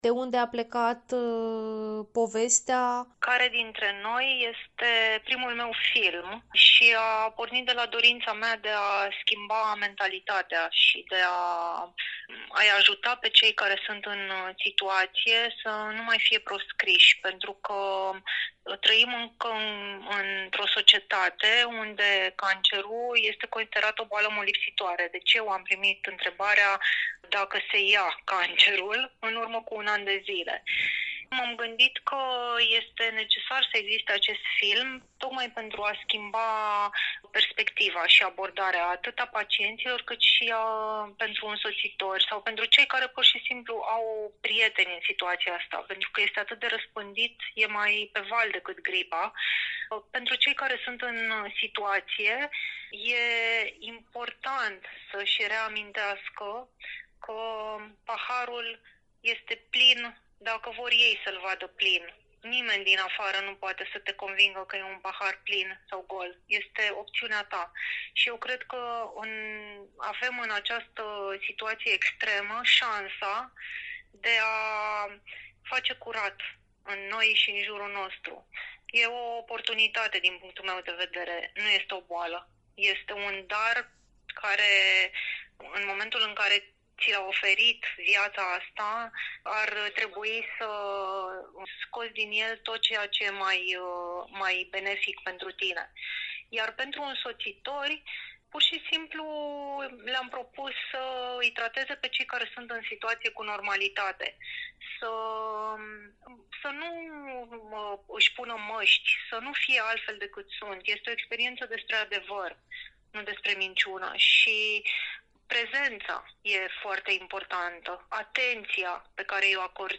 a realizat un interviu